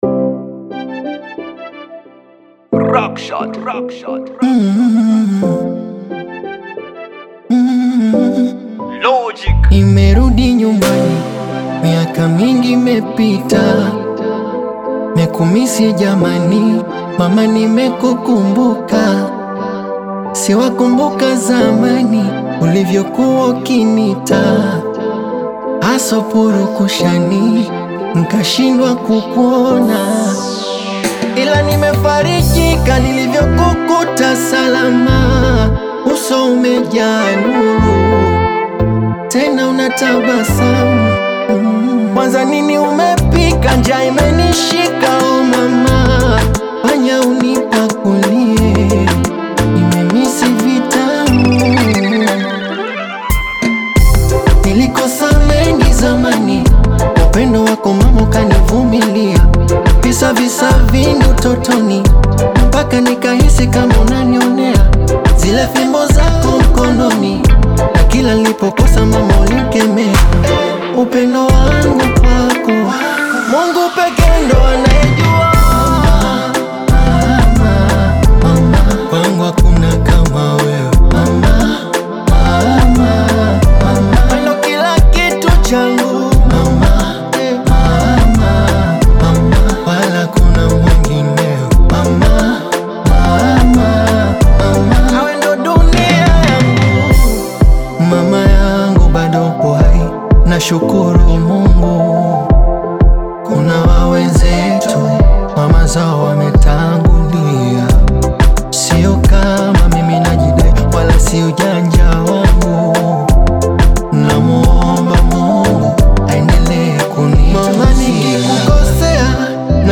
Bongo Fleva